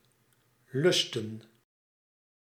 Ääntäminen
IPA : /dɪɡ/ US : IPA : [dɪɡ]